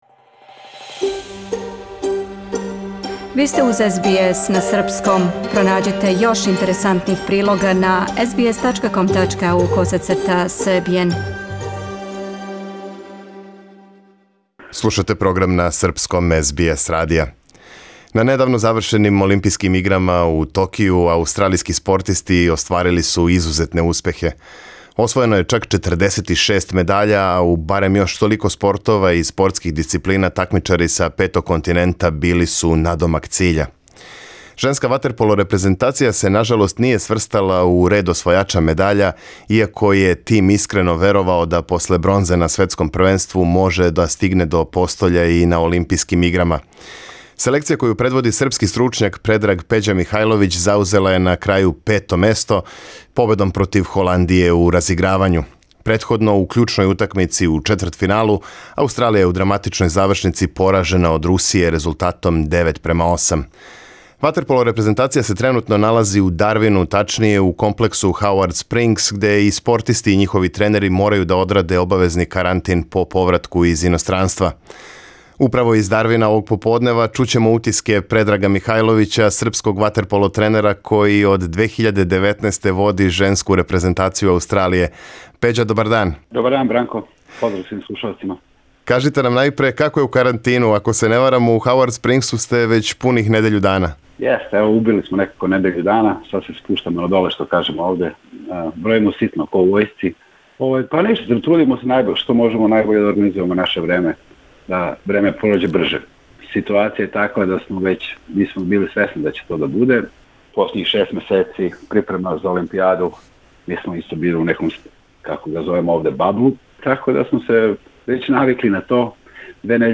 говори за СБС радио из карантина у Дарвину